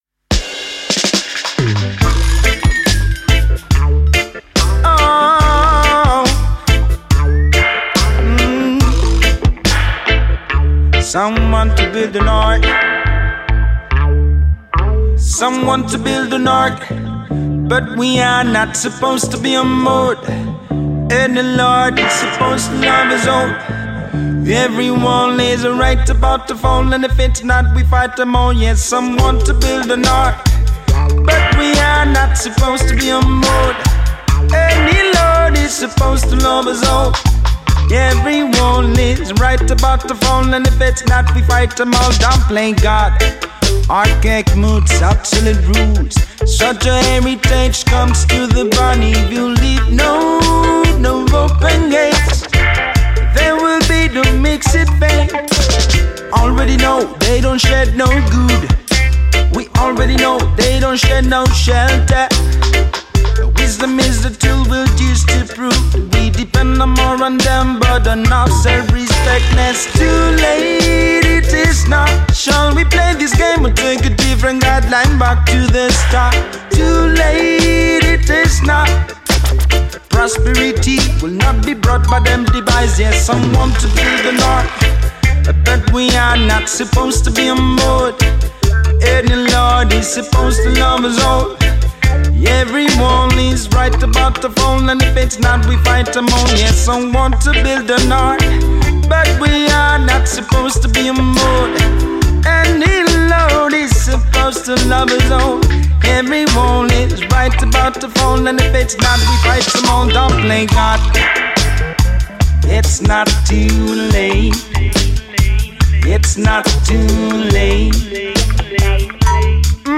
roots music project
vocals
roots track